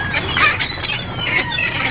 Seagull2
SEAGULL2.wav